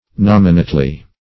nominately - definition of nominately - synonyms, pronunciation, spelling from Free Dictionary Search Result for " nominately" : The Collaborative International Dictionary of English v.0.48: Nominately \Nom"i*nate*ly\, adv.